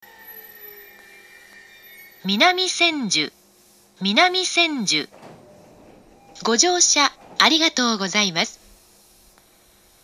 ２０１４年１２月１５日には、２０１５年３月開業の上野東京ラインに対応するため、自動放送の男声が変更されています。
２番線到着放送